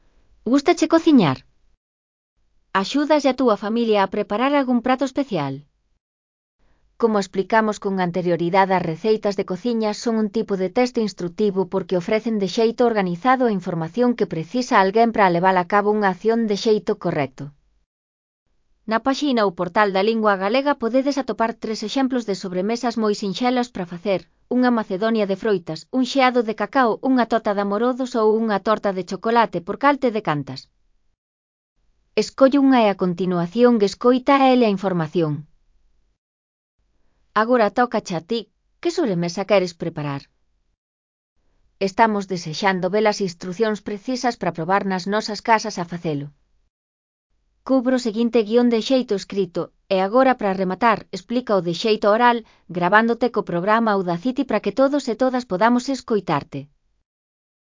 Elaboración propia (Proxecto cREAgal) con apoio de IA, voz sintética xerada co modelo Celtia.. Como facer unhas sobremesas para chuparse os dedos.